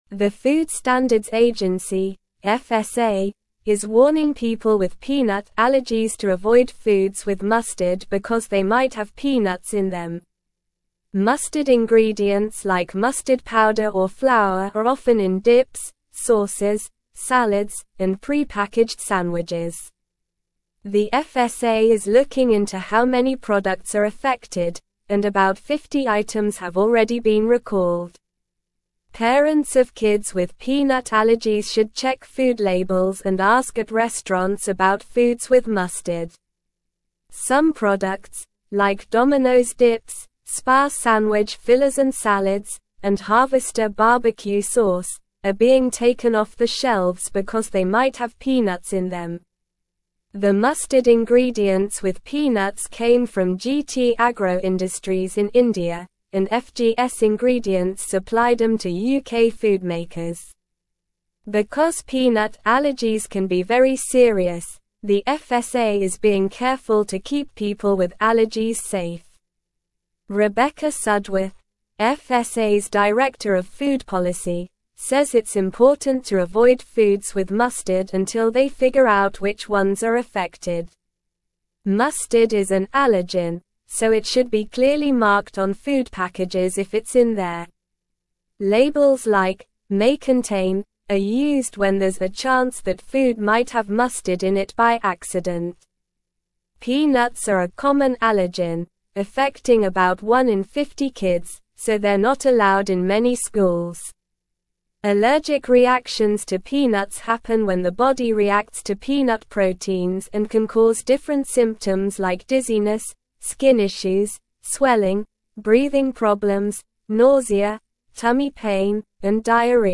Slow
English-Newsroom-Upper-Intermediate-SLOW-Reading-FSA-Warns-of-Peanut-Contamination-in-Mustard-Products.mp3